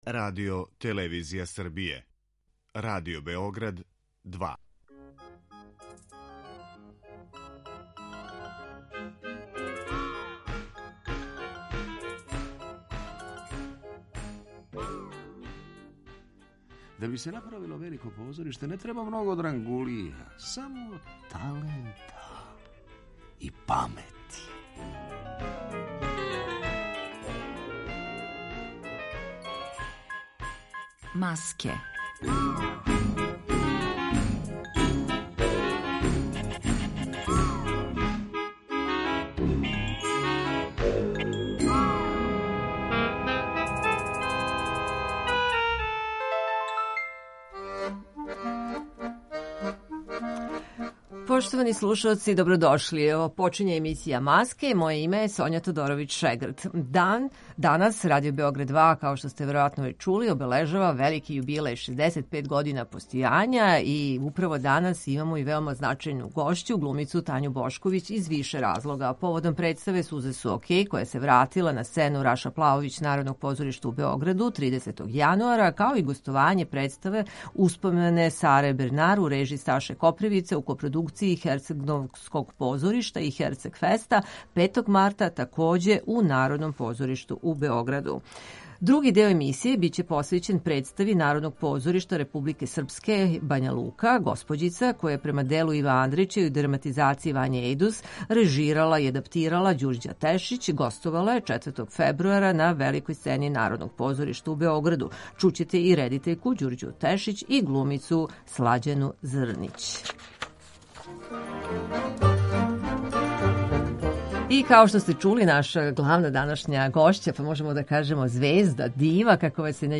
Гошћа емисије Маске биће глумица Тања Бошковић поводом представе „Сузе су океј", која се вратила на Сцену „Раша Плаовић" Народног позоришта у Београду, као и о гостовање представе „Успомене Саре Бернар" у режији Сташе Копривице, у копродукцији херцегновског позоришта и Херцег феста.